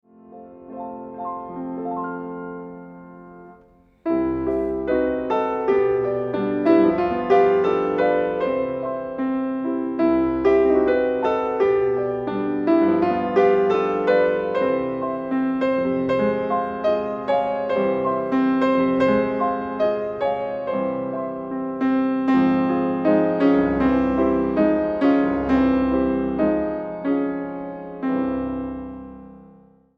• liebliche Klänge und Melodien, die Sie motivieren